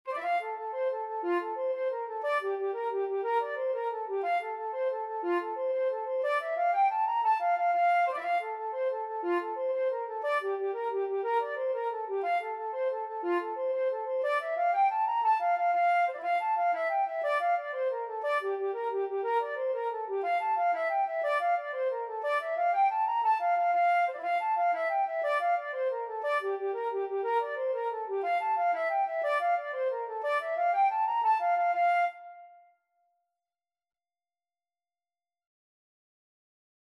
F major (Sounding Pitch) (View more F major Music for Flute )
6/8 (View more 6/8 Music)
F5-Bb6
Flute  (View more Intermediate Flute Music)
Traditional (View more Traditional Flute Music)